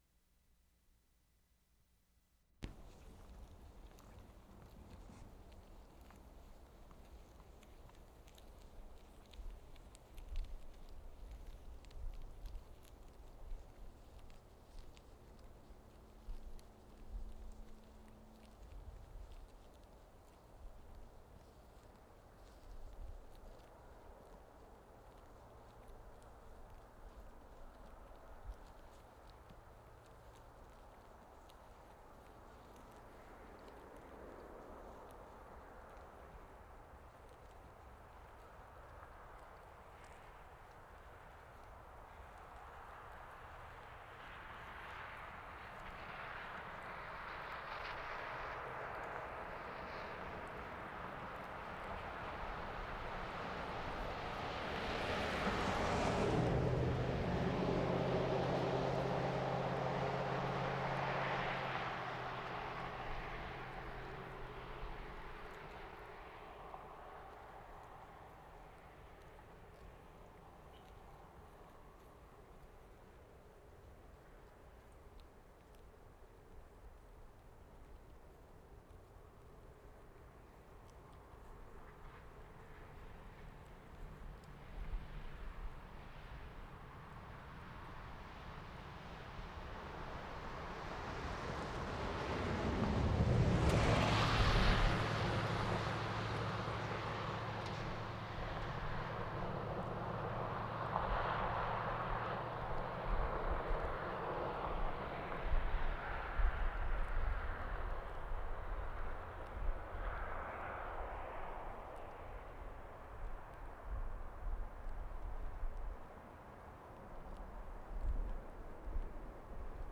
KOOTENAY NATIONAL PARK, B.C.
ELK FEEDING BY ROADSIDE 2'30"
10. A group of female elk browsing by the roadside. Munching noises clear at beginning, then cars approaching and passing at high speed. Note the long approach time of first car. Never gets as quiet as beginning again.